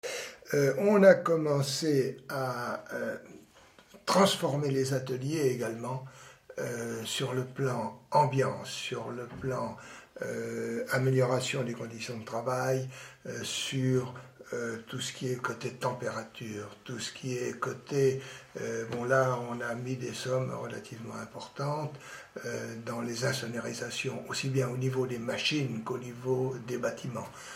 Figure 19 : Extrait vidéo du témoignage d’un responsable de bureau d’études.
Cet article s’appuie sur une collecte audiovisuelle de la mémoire de l’industrie de la ville de Vierzon donnant lieu à la création d’un site Internet.